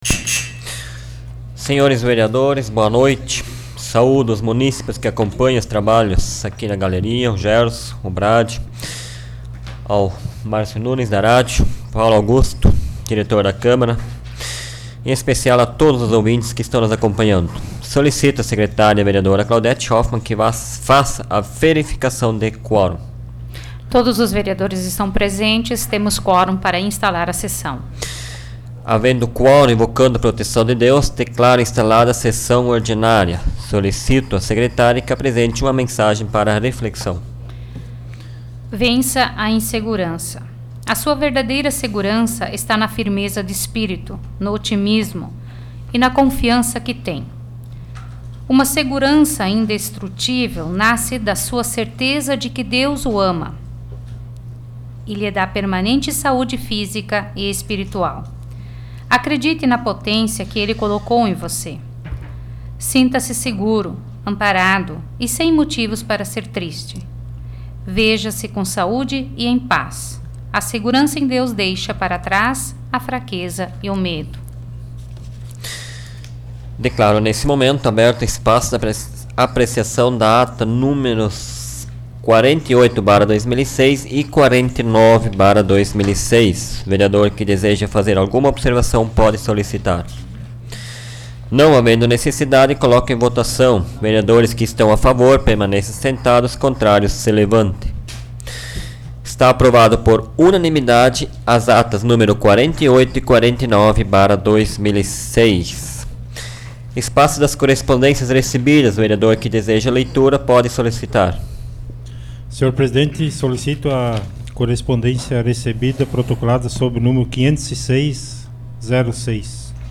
Áudio da 73ª Sessão Plenária Ordinária da 12ª Legislatura, de 11 de dezembro de 2006